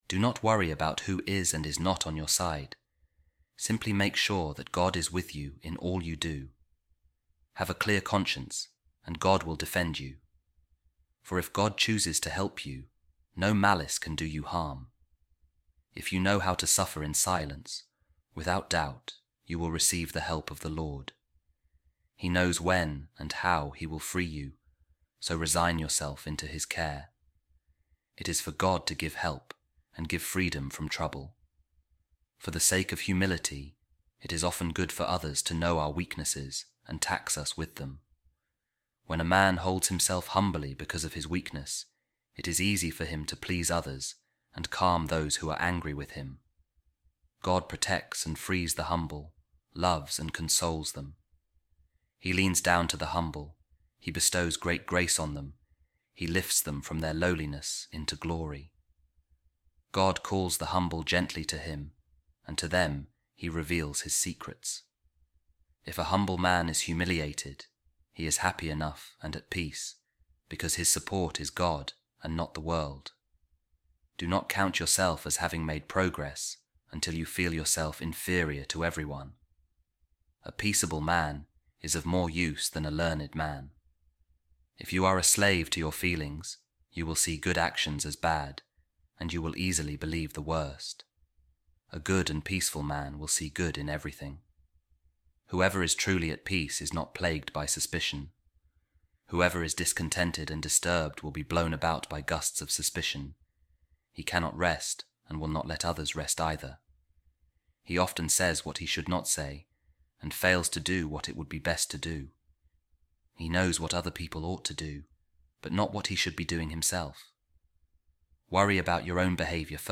A Reading From The Imitation Of Christ | Of Humble Submission, Of The Good, Peaceable Man